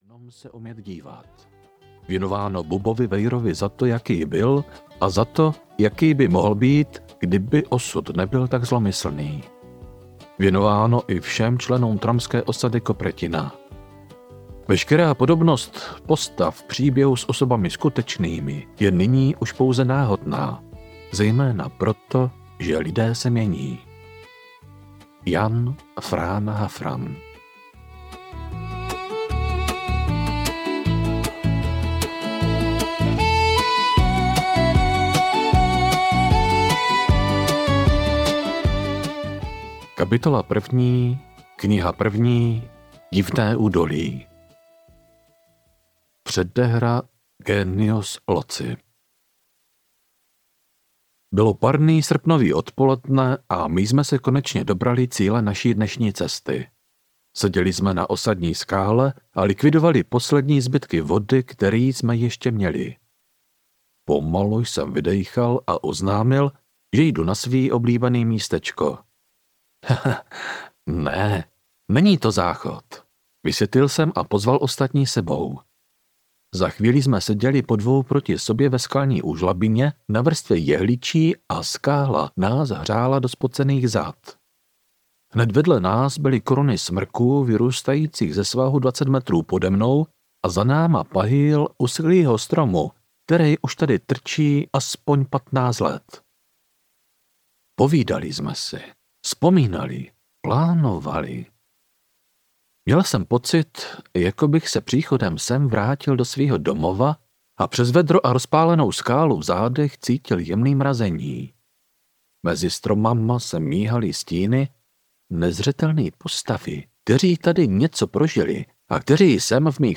Divné údolí audiokniha
Ukázka z knihy